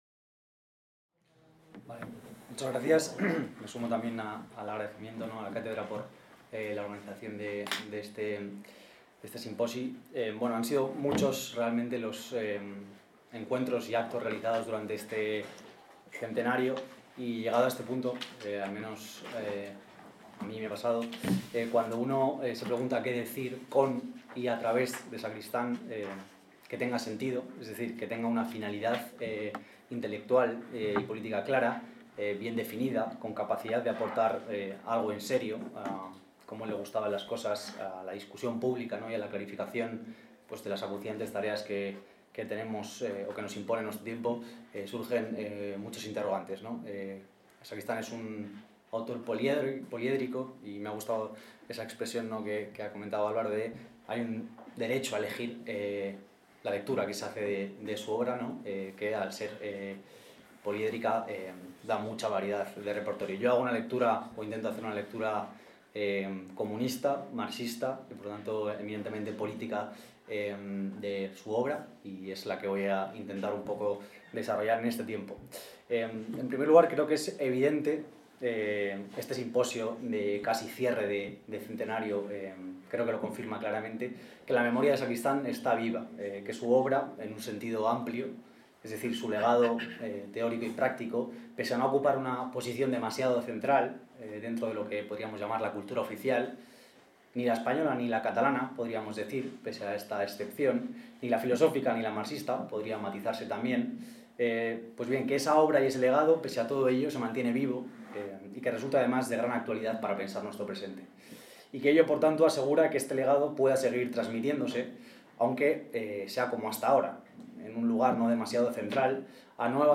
>  Aquesta conferència s'emmarca dins el Simposi Trias 2025, organitzat per la Càtedra Ferrater Mora, en col·laboració amb el Memorial Democràtic i dedicat al filòsof Manuel Sacristán.